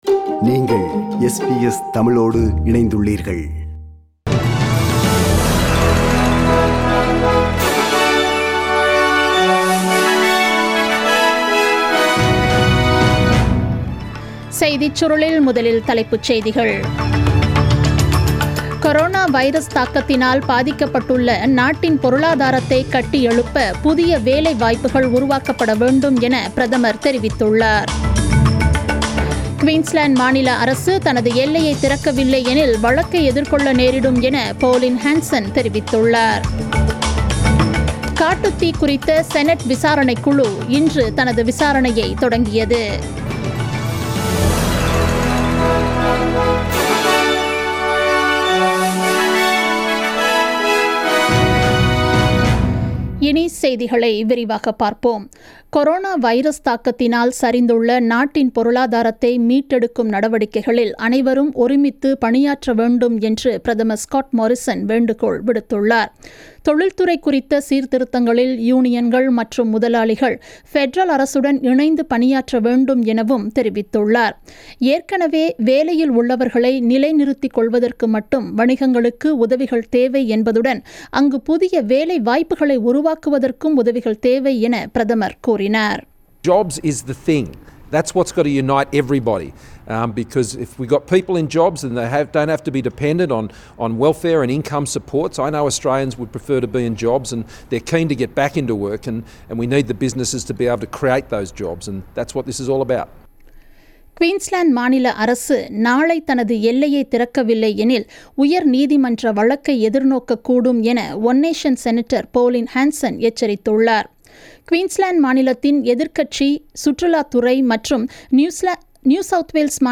The news bulletin aired on 27th May 2020 at 8pm